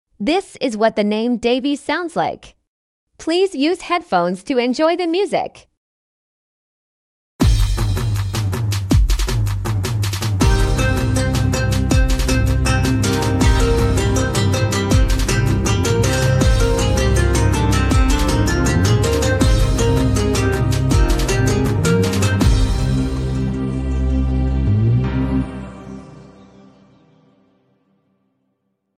How the name Davy sounds like as midi art.